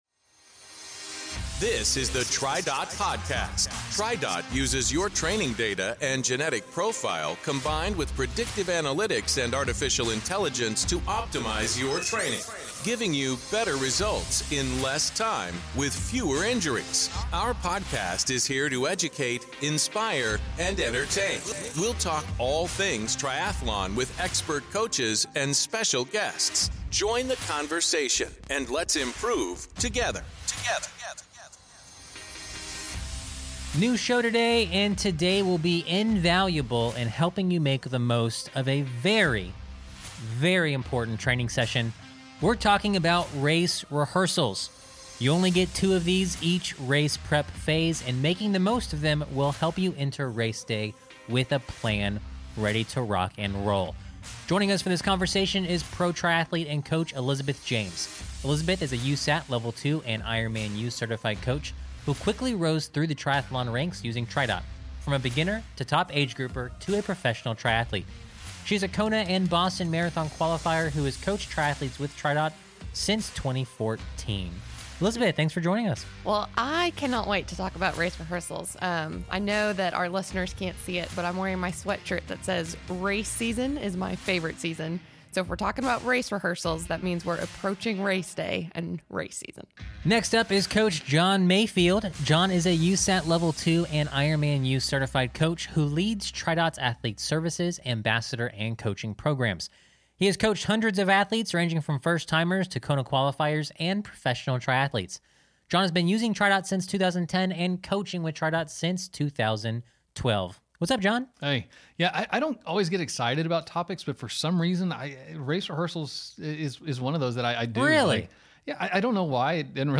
The TriDot Triathlon Podcast